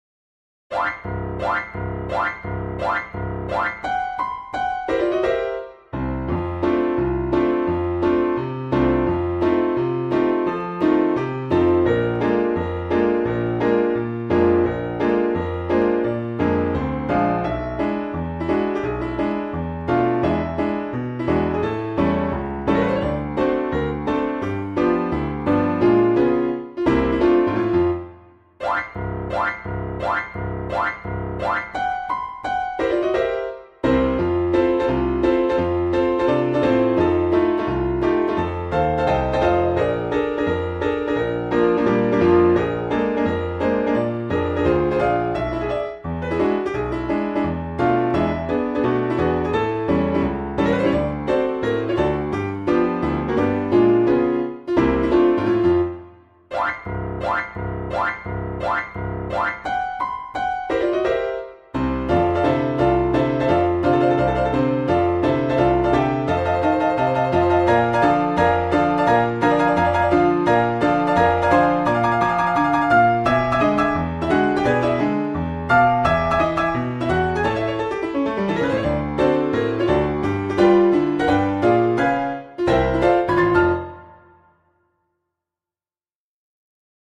• Теги: Ноты для фортепиано
Ноты для фортепиано.